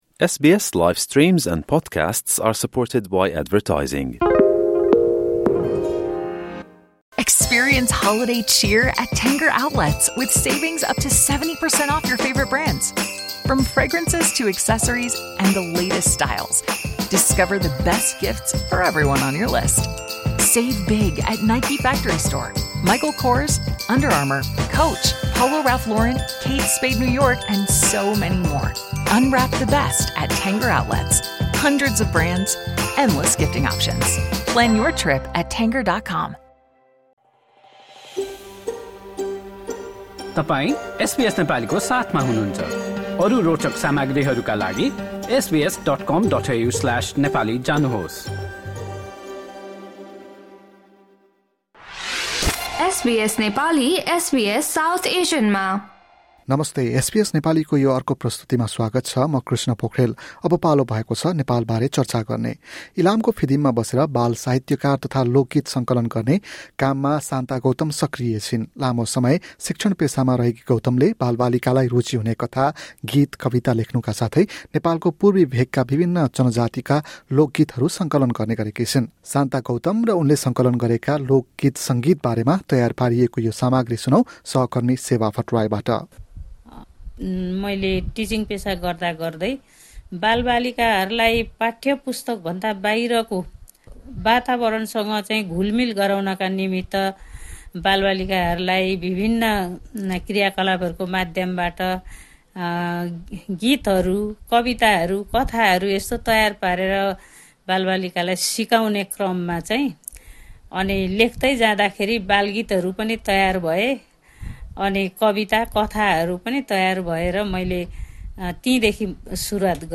बाल साहित्य बारे एसबीएस नेपालीसँग गरेको कुराकानी सुन्नुहोस्।